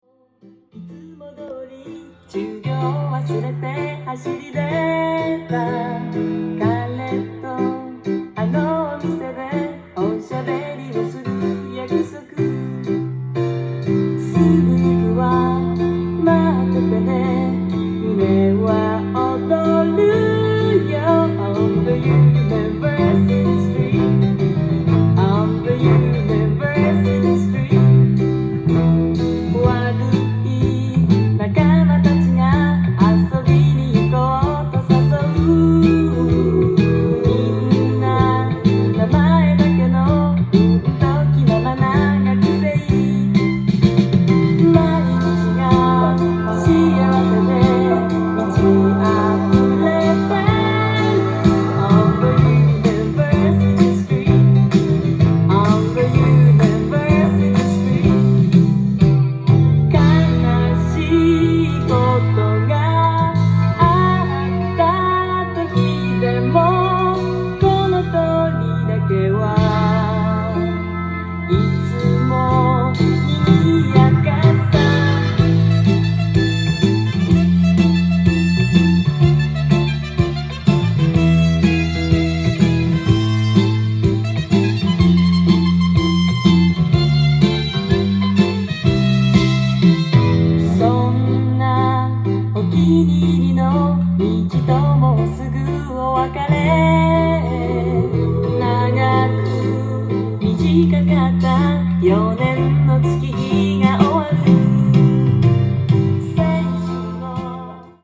ジャンル：CITYPOP / POP
店頭で録音した音源の為、多少の外部音や音質の悪さはございますが、サンプルとしてご視聴ください。